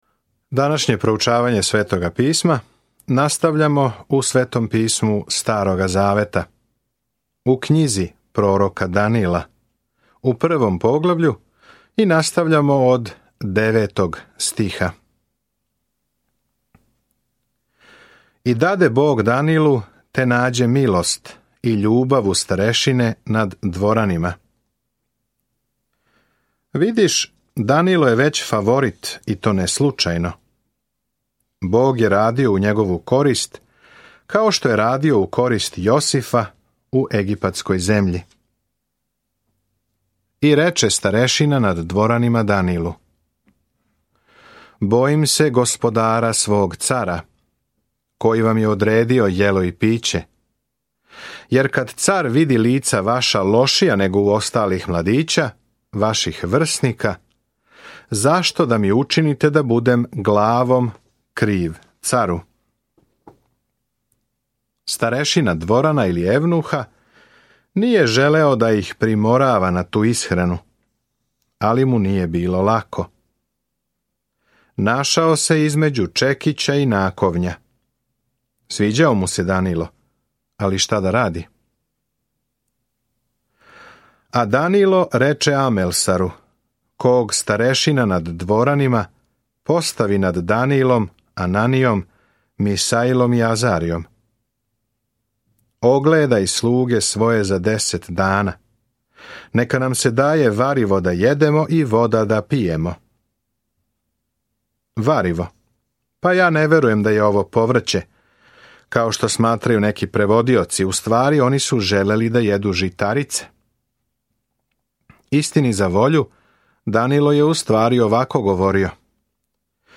Sveto Pismo Knjiga proroka Danila 1:9-21 Dan 2 Započni ovaj plan Dan 4 O ovom planu Књига пророка Данила је и биографија човека који је веровао Богу и пророчка визија о томе ко ће на крају завладати светом. Свакодневно путујте кроз Данила док слушате аудио студију и читате одабране стихове из Божје речи.